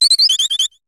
Cri de Passerouge dans Pokémon HOME.